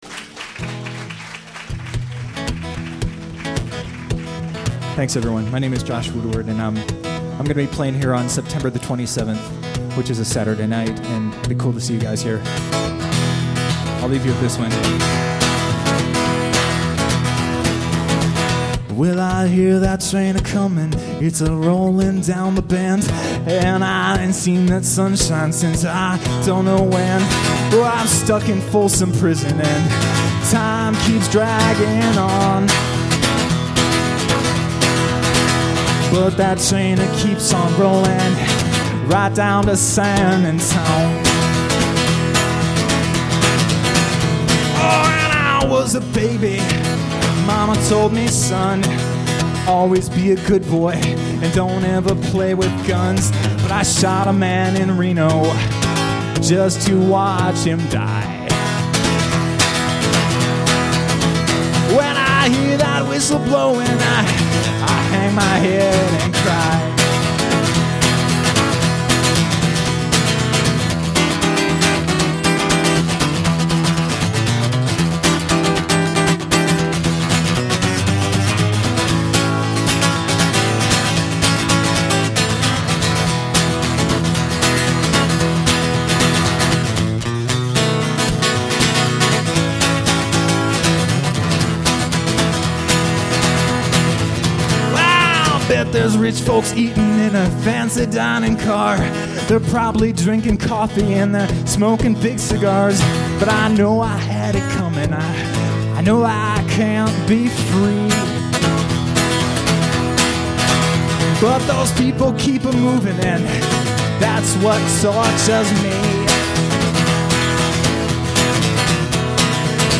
raucous cover